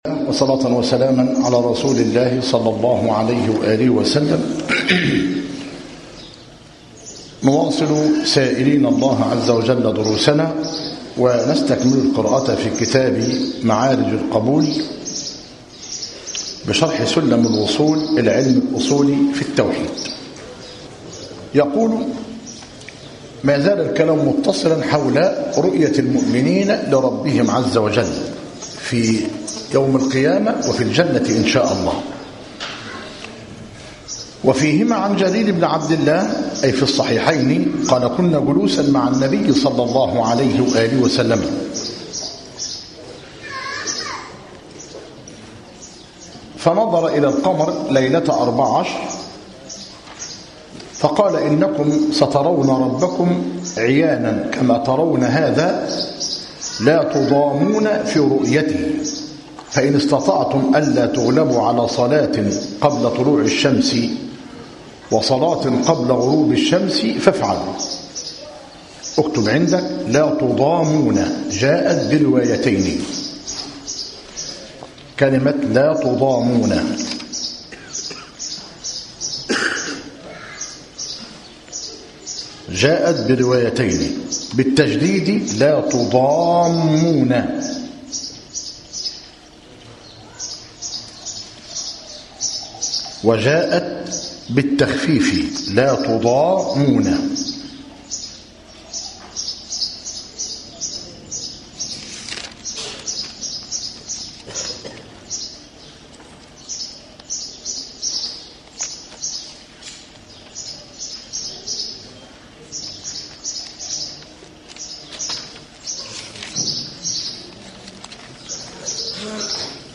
معارج القبول شرح سلم الوصول للحكمي رحمه الله - مسجد التوحيد - ميت الرخا - زفتى - غربية - المحاضرة السابعة عشر - بدء من قوله " وفيهما عن جرير بن عبد الله قال كنا جلوسا مع ...